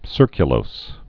(sûrkyə-lōs)